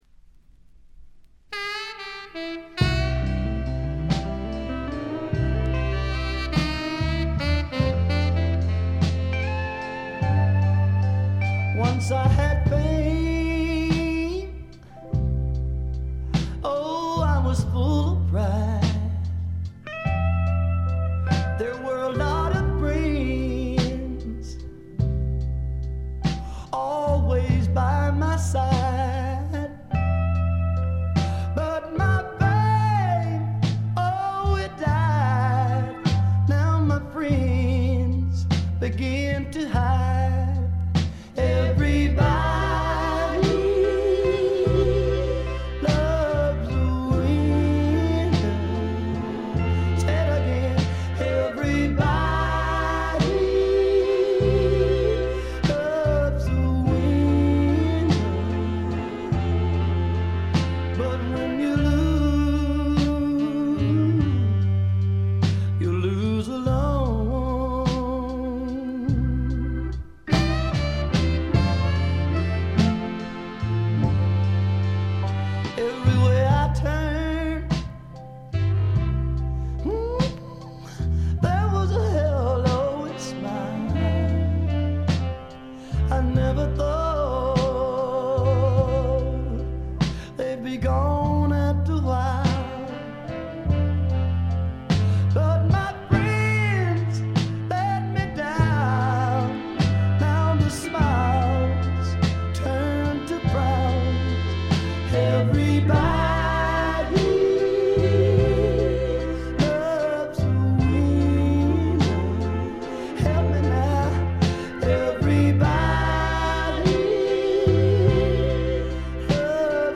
ほとんどノイズ感無し。
南部ソウル完璧な一枚。
試聴曲は現品からの取り込み音源です。